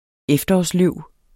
Udtale [ ˈεfdʌɒs- ]